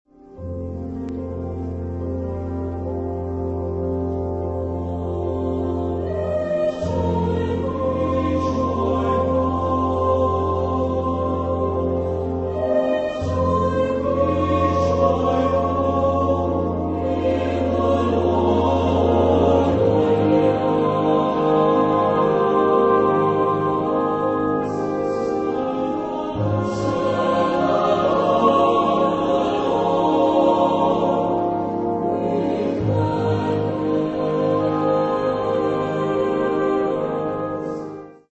Genre-Style-Forme : Chœur ; Sacré
Type de choeur : SATB  (4 voix mixtes )
Solistes : Soprano (1) OU Ténor (1)  (1 soliste(s))
Instruments : Piano (1)
Tonalité : la bémol majeur